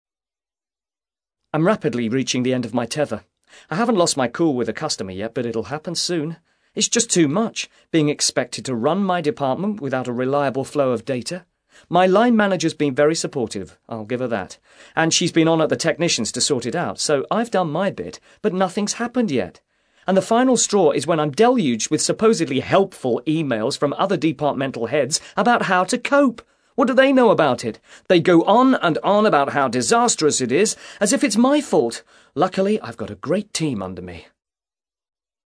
ACTIVITY 162: You will hear five short extracts in which five people are talking about problems related to their work.